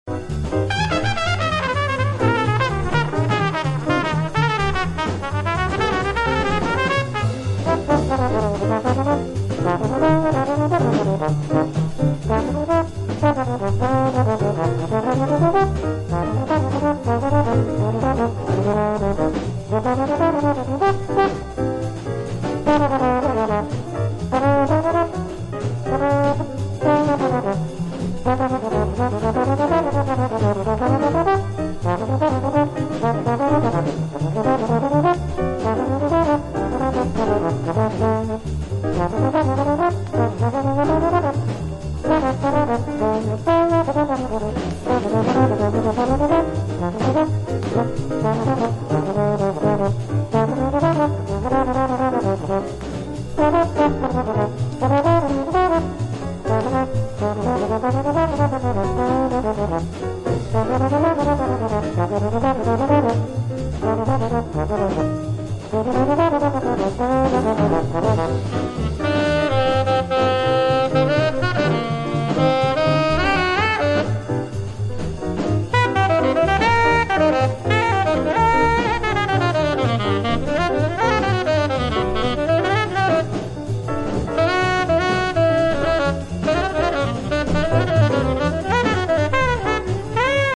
recorded on 15 September 1957
Jazz